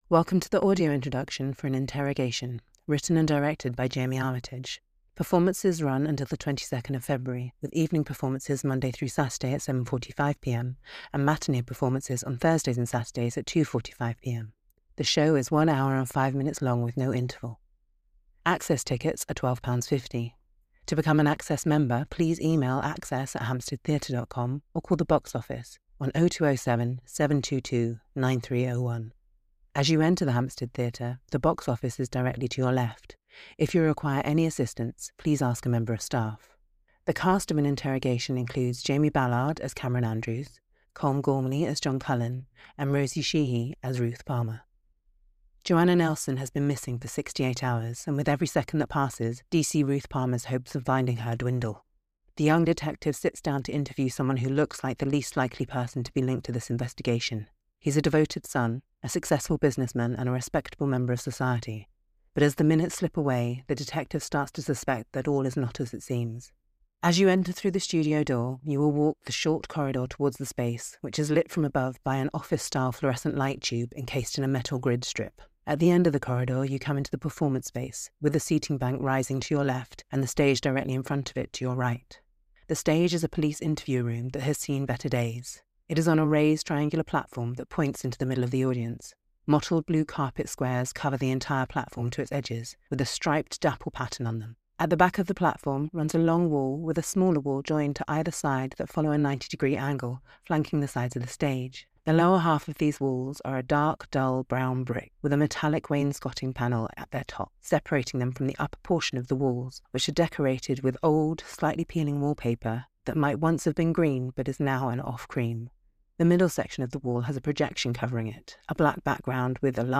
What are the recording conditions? An Audio Introduction is available for this production. This is a pre-recorded introduction describing the set, characters and costumes and includes an interview with the cast.